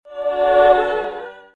ragnarok-online-priest-blessing_25714.mp3